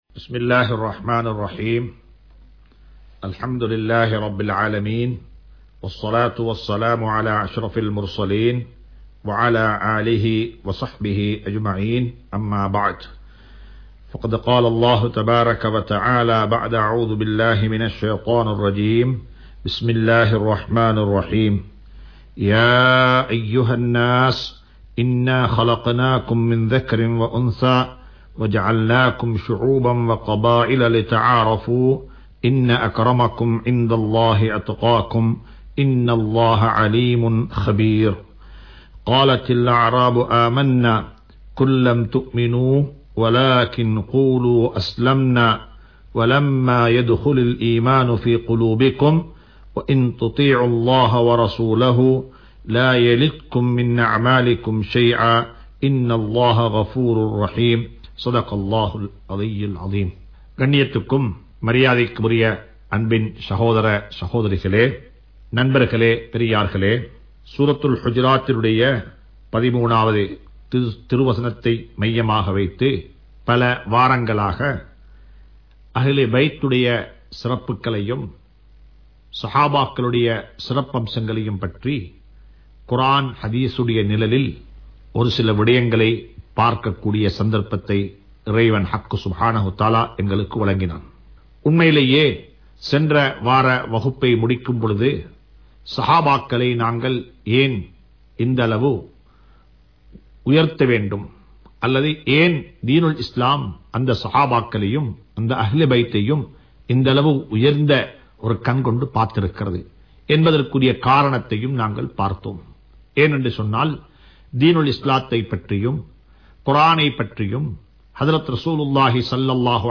Raulathul Quran 110(Sura Hujurat) | Audio Bayans | All Ceylon Muslim Youth Community | Addalaichenai